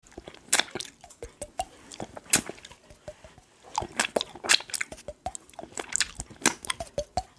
ASMR Lollipop Eating & Mouth Sound Effects Free Download